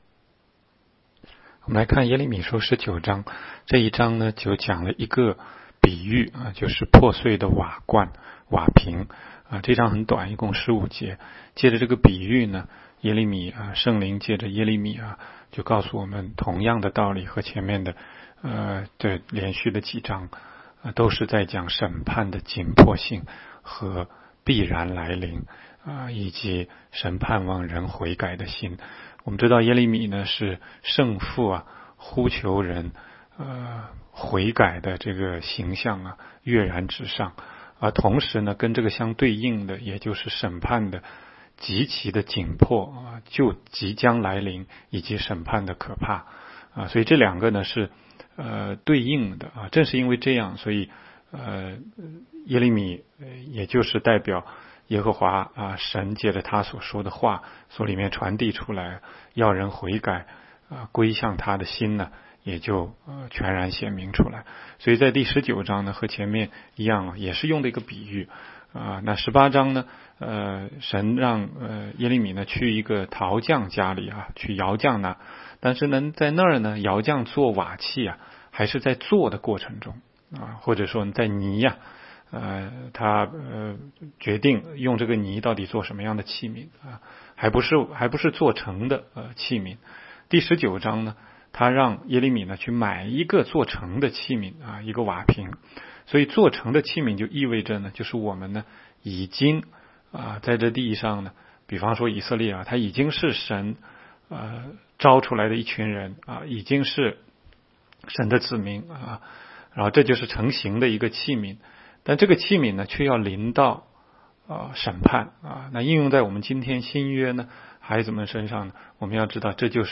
16街讲道录音 - 每日读经 -《耶利米书》19章